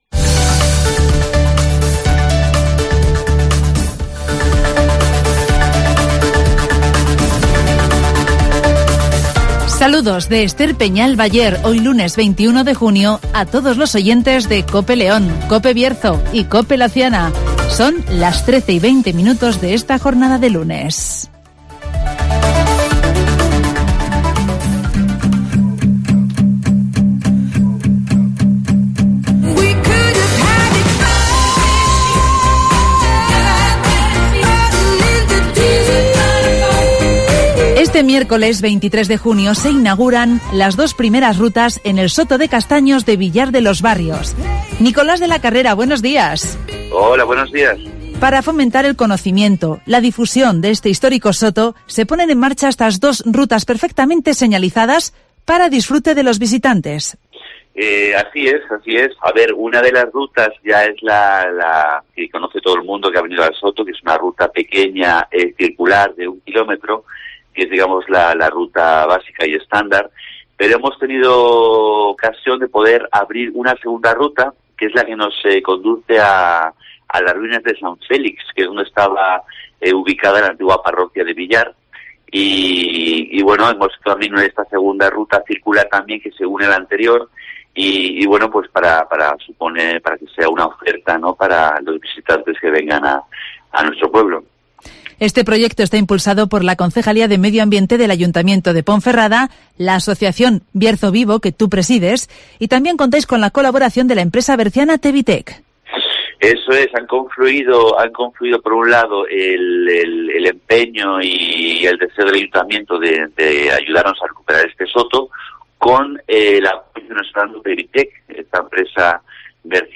ACTUALIDAD